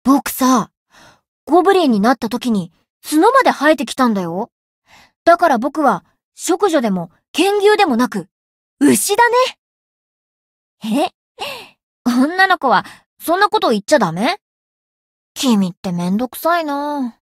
灵魂潮汐-莉莉艾洛-七夕（摸头语音）.ogg